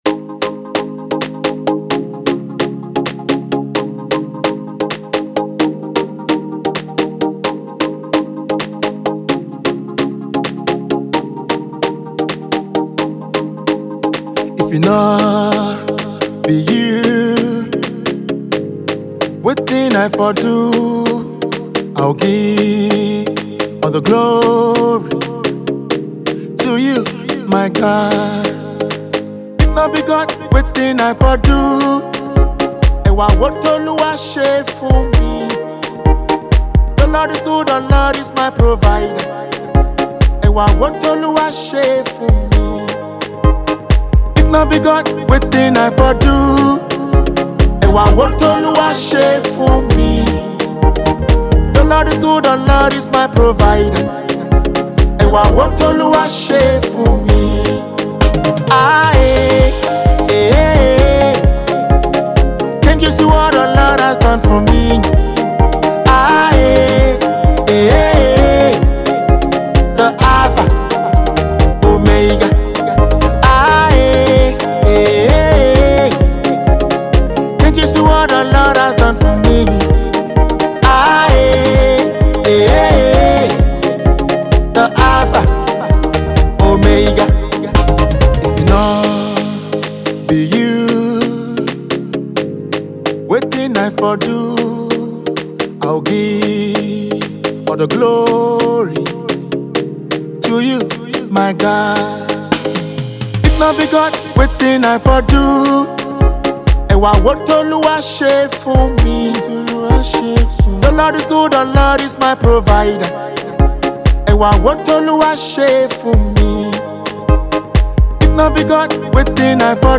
Gospel music minister